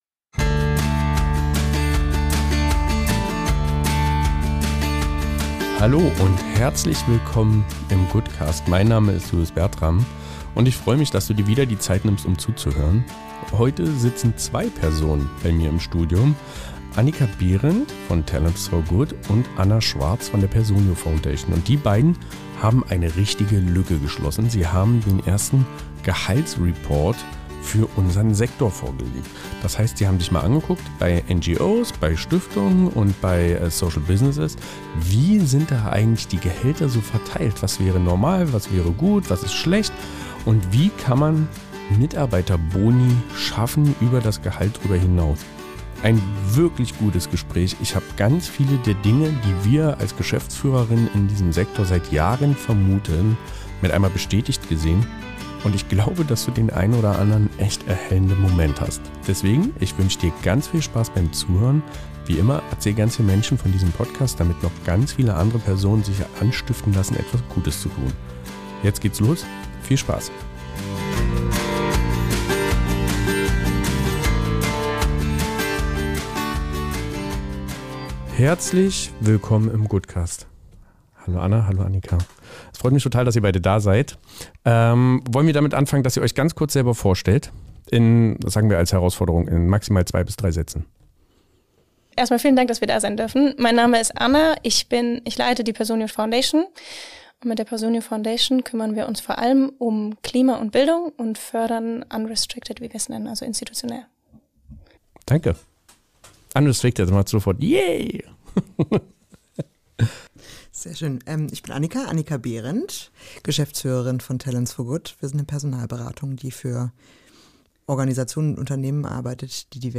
Ein Gespräch voller spannender Fakten, ehrlicher Einordnungen und der einen oder anderen unbequemen Wahrheit.